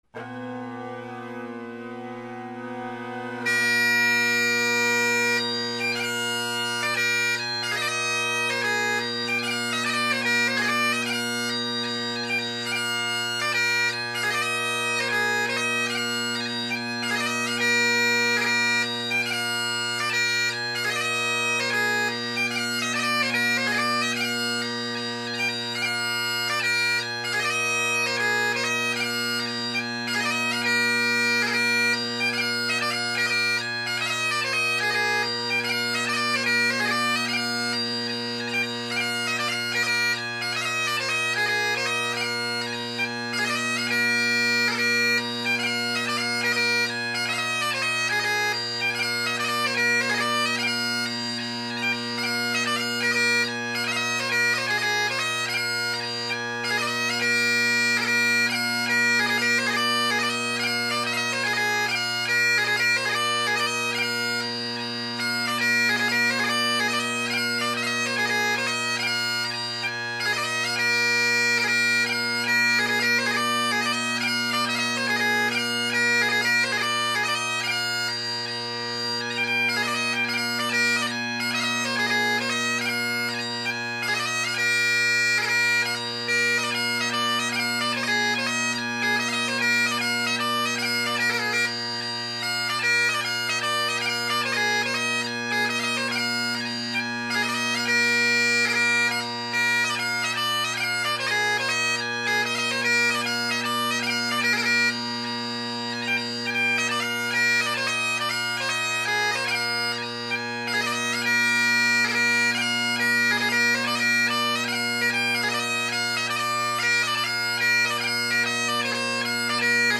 Great Highland Bagpipe Solo, Tune of the Month
The pipes were going pretty well so I figured I’d challenge myself as I work to get my chops back from the long string of illnesses.
Jeannie Carruthers, The Cowal Gathering, Inveraray Castle, Susan MacLeod, Captain Lachlan MacPhail of Tiree, and Alick C. McGregor – an MMSSRR in the style of the Former Winners March Strathspey and Reel competition at the Northern Meeting.
Colin Kyo with full Ezeedrone reeds and an old beat up Gilmour chanter reed in an older CK chanter.